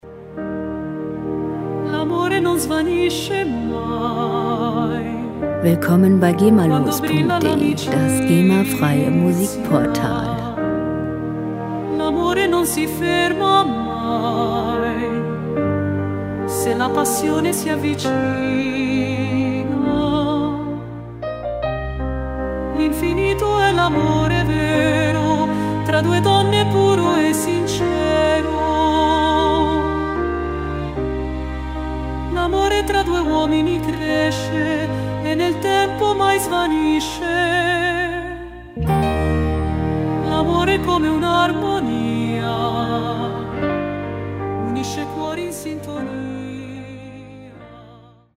Klassik Pop
Musikstil: Classical Crossover
Tempo: 70 bpm
Tonart: Es-Dur
Charakter: romantisch, gefühlvoll